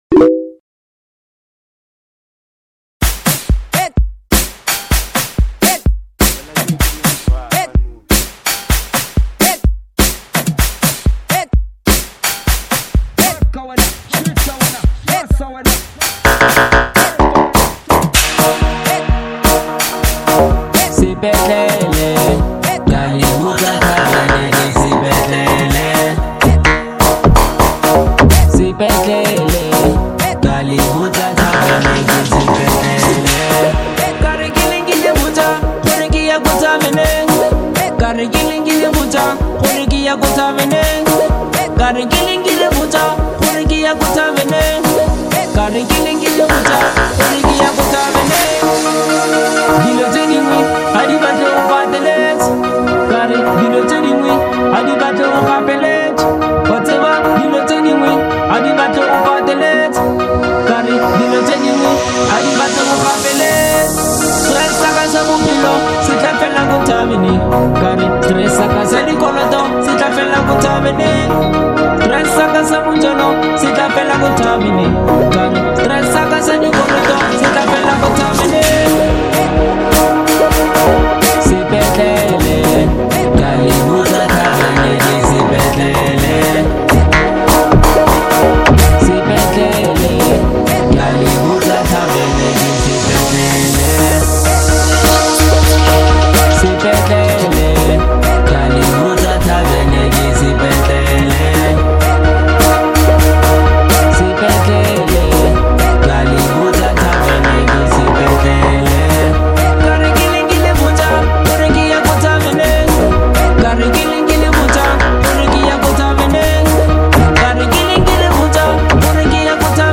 Bolo House